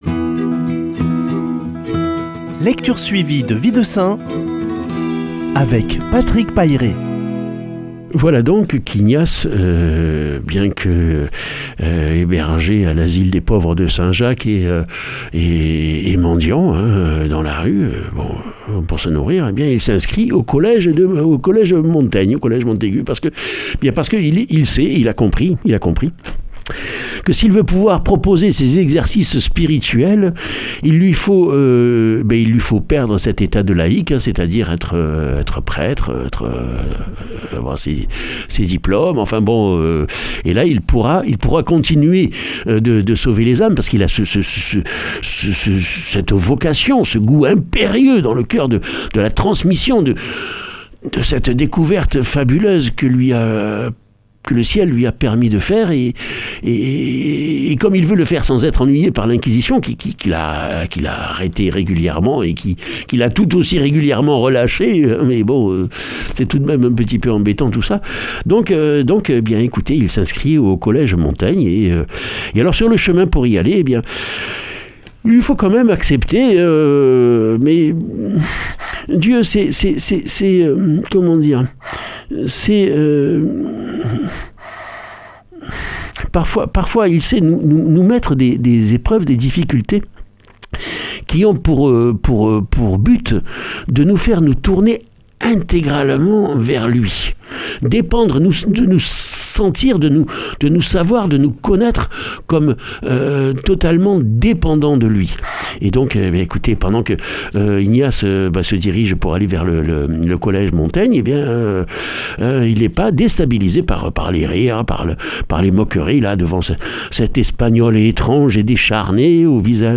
Lecture suivie de la vie des saints - Radio Présence